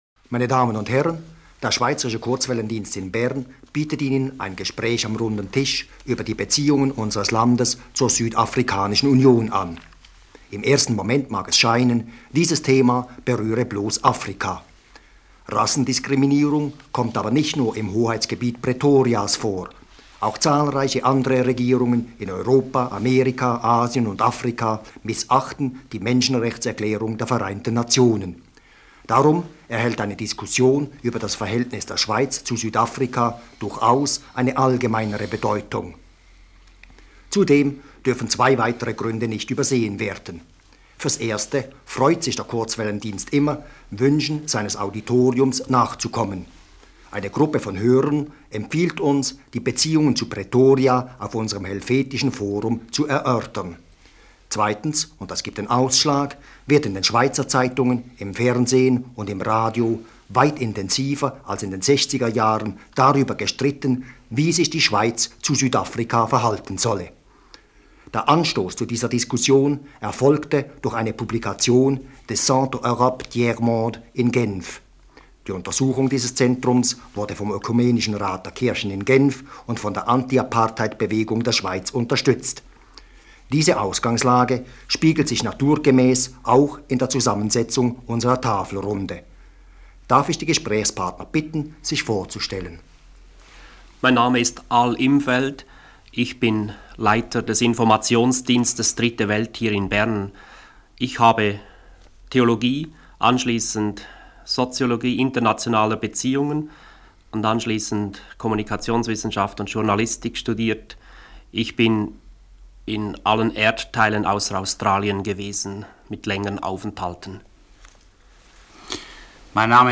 Runder Tisch zu Beziehungen Schweiz-Südafrika (1973)
Am 21. Juni 1973 führte der Schweizerische Kurzwellendienst in Bern, später bekannt als Schweizer Radio International, auf Hörerwunsch einen Runden Tisch durch über die Beziehungen der Schweiz zur Südafrikanischen Union.